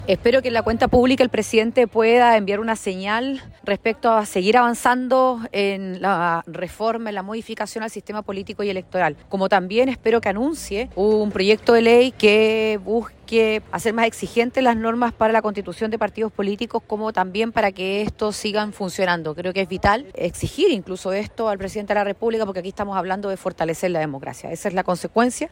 Ante ello, la presidenta de la comisión de Constitución de la Cámara Alta, Paulina Núñez (RN), comentó que espera que el mandatario ponga énfasis en la conformación de partidos.